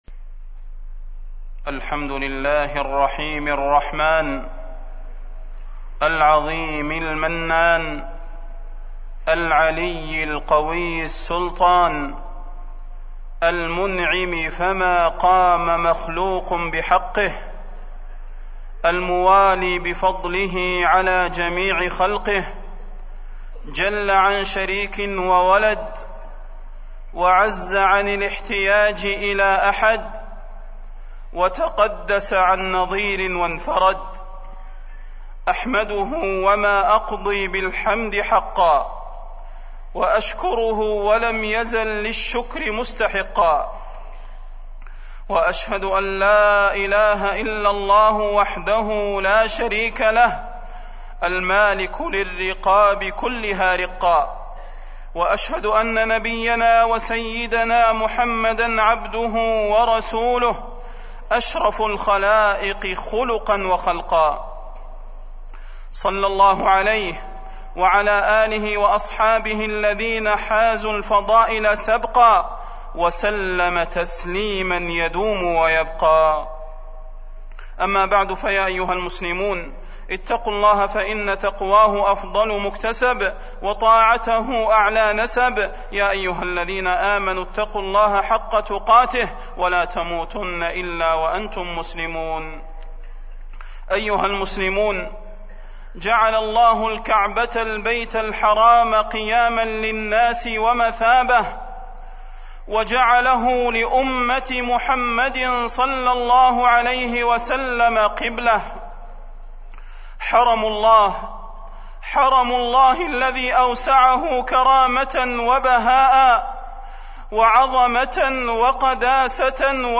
تاريخ النشر ٦ ذو الحجة ١٤٢٦ هـ المكان: المسجد النبوي الشيخ: فضيلة الشيخ د. صلاح بن محمد البدير فضيلة الشيخ د. صلاح بن محمد البدير الحج